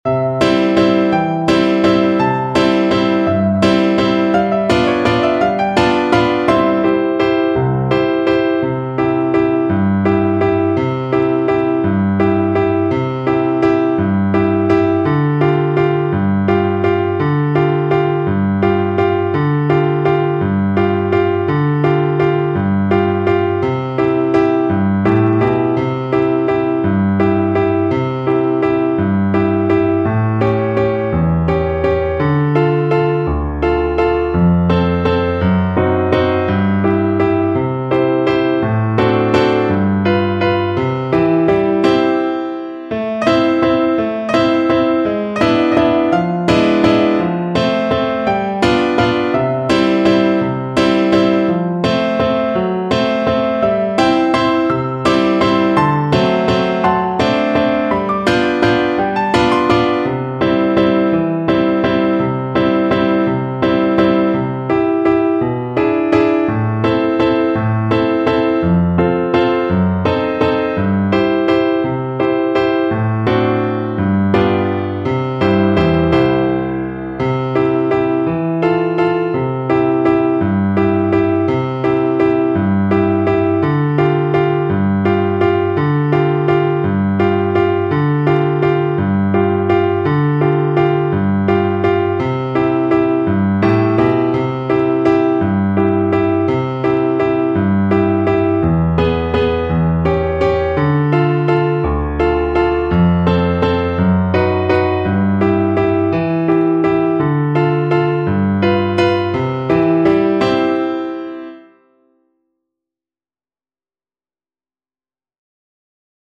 3/4 (View more 3/4 Music)
One in a bar .=56
Tin Pan Alley Songs for Violin and Piano